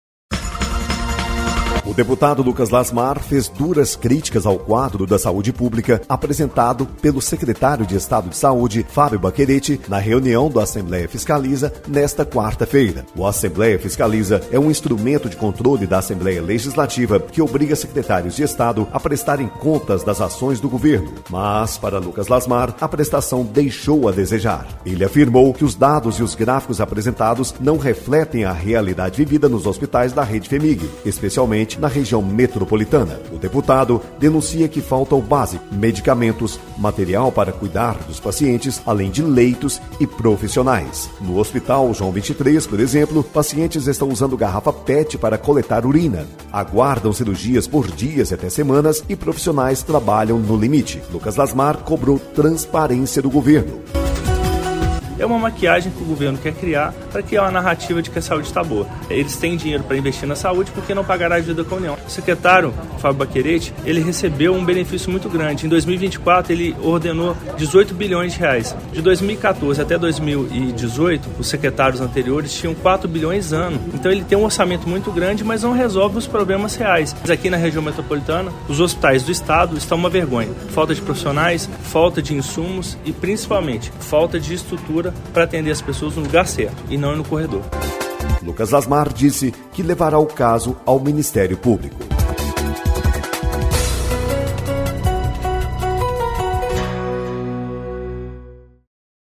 O deputado Lucas Lasmar faz críticas ao Secretário de Saúde durante o Assembleia Fiscaliza Críticas no Assembleia Fiscaliza da SES.
Boletim de Rádio